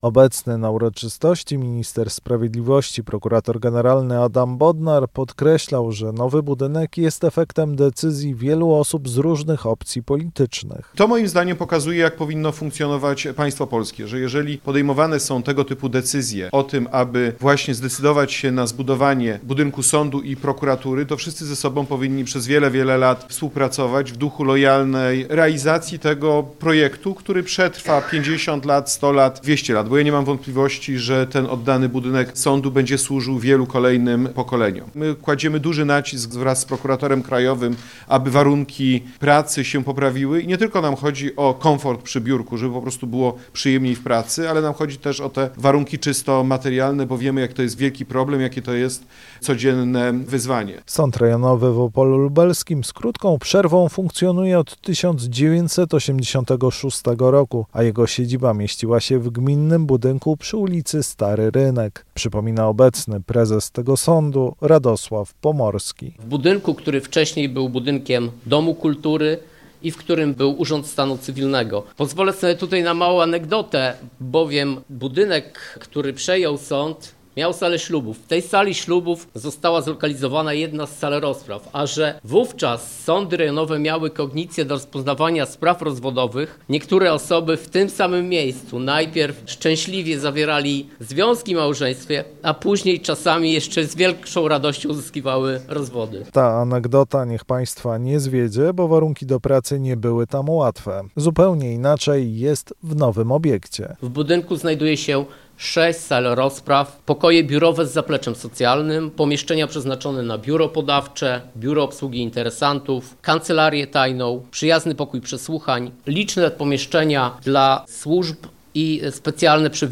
Obecny na uroczystości minister sprawiedliwości, prokurator generalny Adam Bodnar podkreślał, że nowy budynek jest efektem decyzji wielu osób z różnych opcji politycznych.